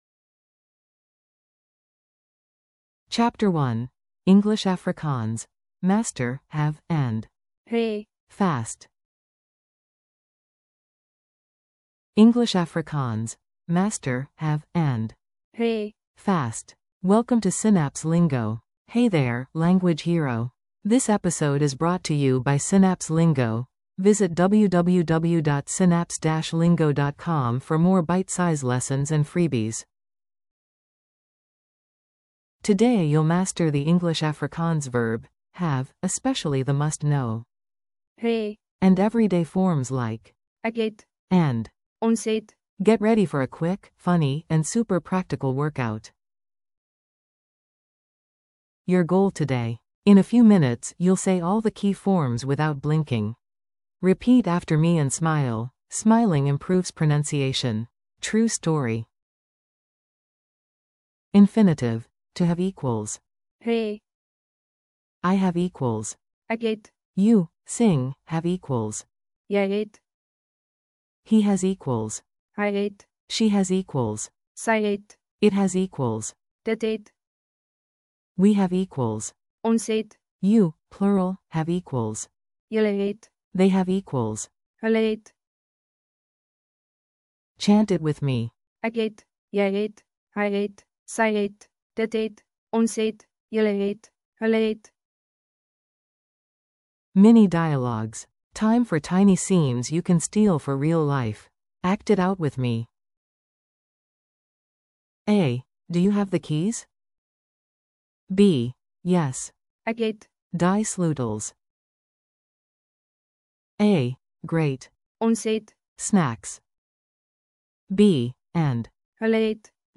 Audio for repeating & practicing
• ✔ eBooks + Audiobooks complete